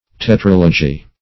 Tetralogy \Te*tral"o*gy\, n. [Gr. ?; te`tra- (see Tetra-) + ?